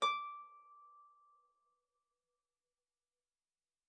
KSHarp_D6_mf.wav